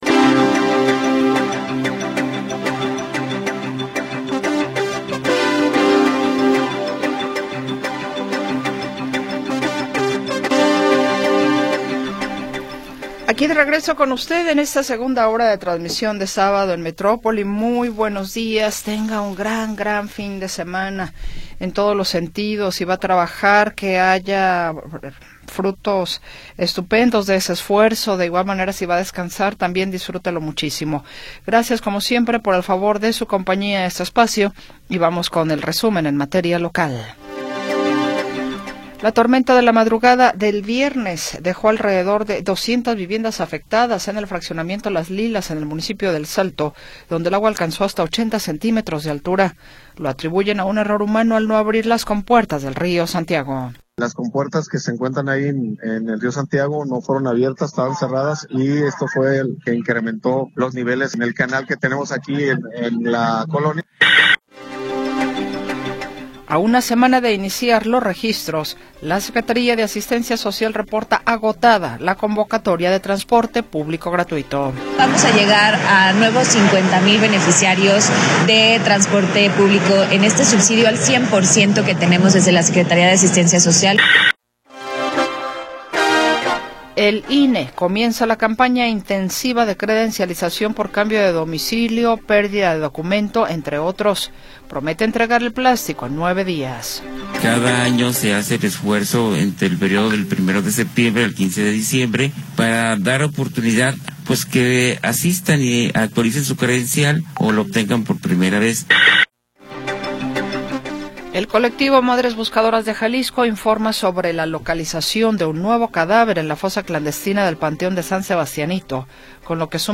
23 de Agosto de 2025 audio Noticias y entrevistas sobre sucesos del momento